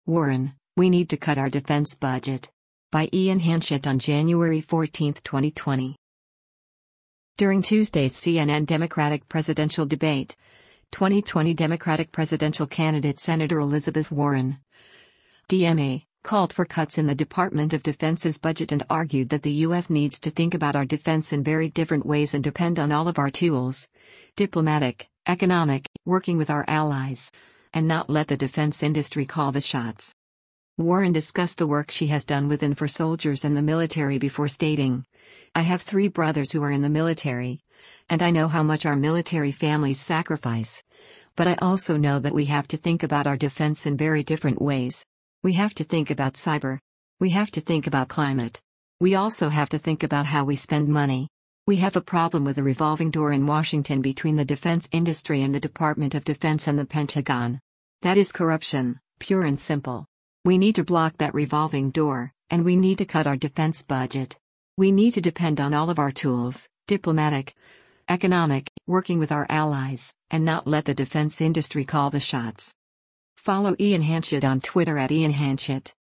During Tuesday’s CNN Democratic presidential debate, 2020 Democratic presidential candidate Sen. Elizabeth Warren (D-MA) called for cuts in the Department of Defense’s budget and argued that the U.S. needs to “think about our defense in very different ways” and “depend on all of our tools, diplomatic, economic, working with our allies, and not let the defense industry call the shots.”